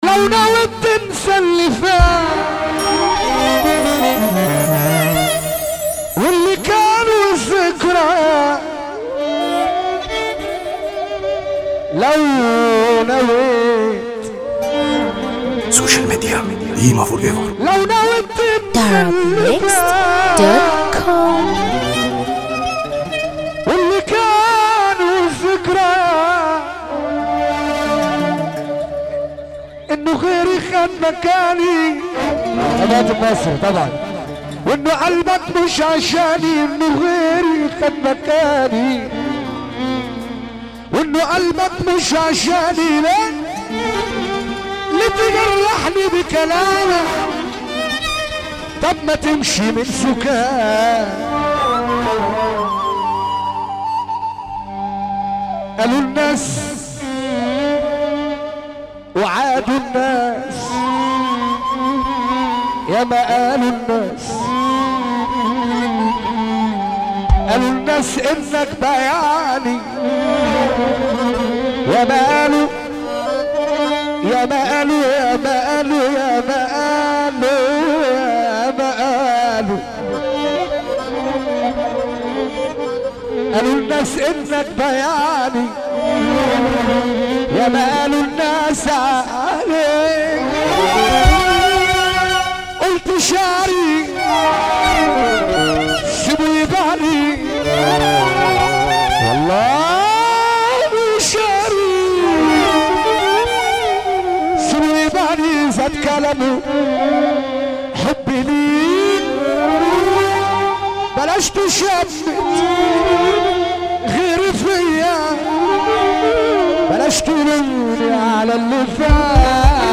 موال
حزينة موت